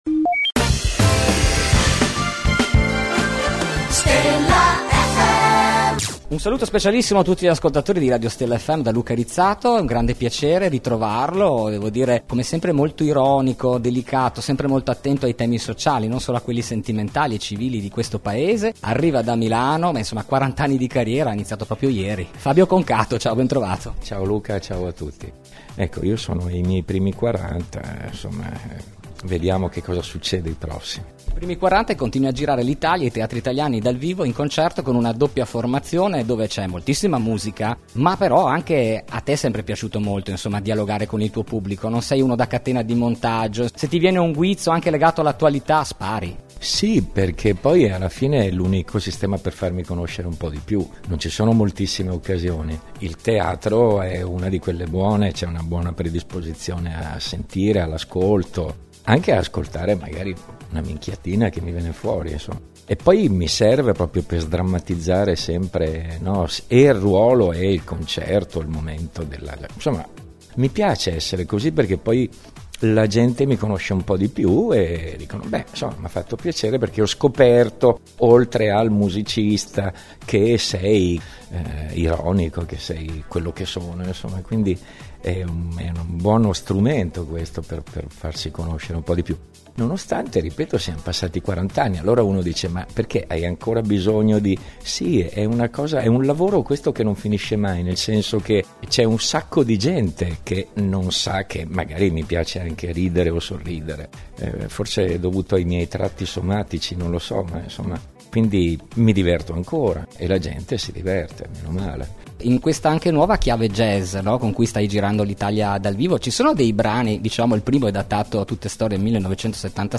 10 Giu Intervista Fabio Concato